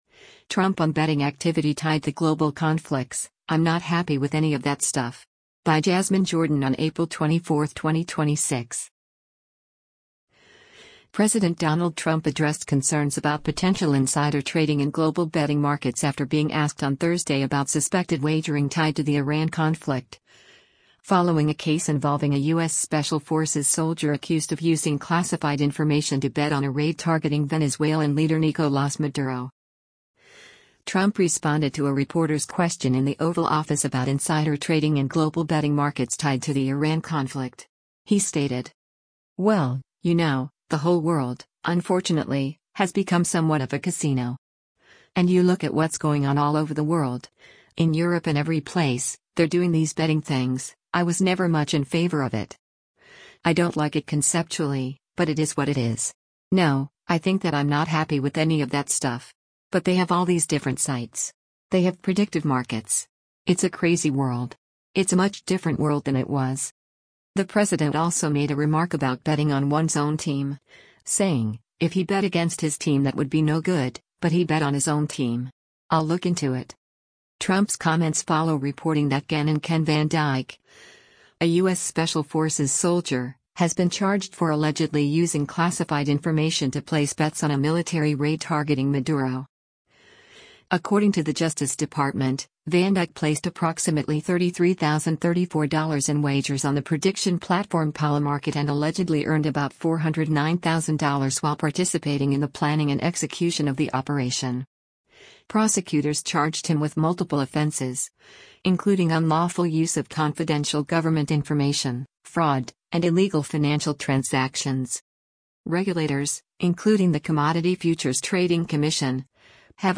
Trump responded to a reporter’s question in the Oval Office about insider trading and global betting markets tied to the Iran conflict.